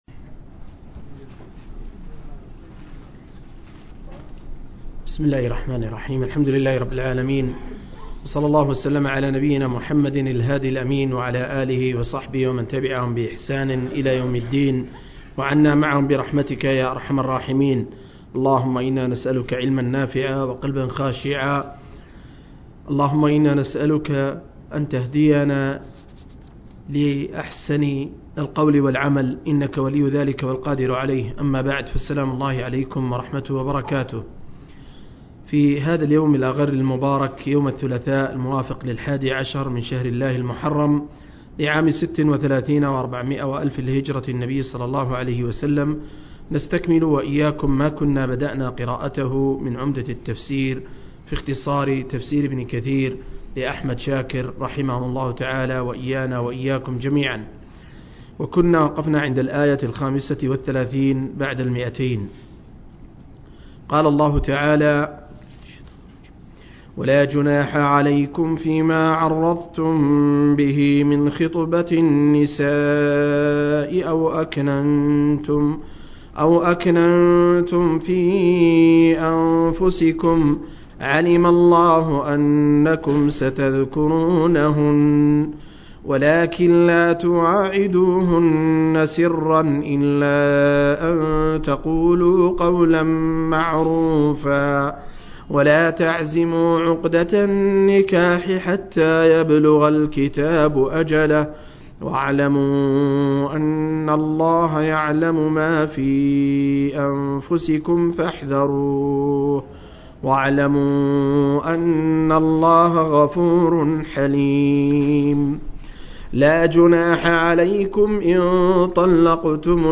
049- عمدة التفسير عن الحافظ ابن كثير – قراءة وتعليق – تفسير سورة البقرة (الآيات 239-235)